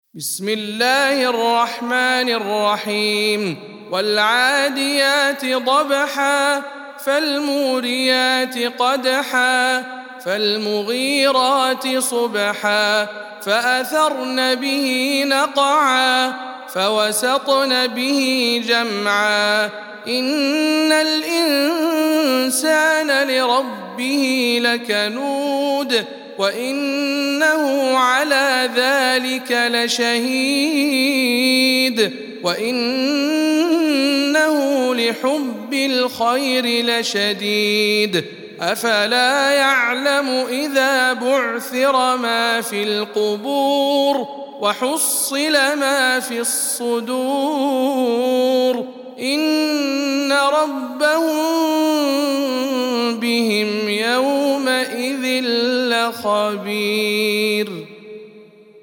سورة العاديات - رواية إدريس عن خلف العاشر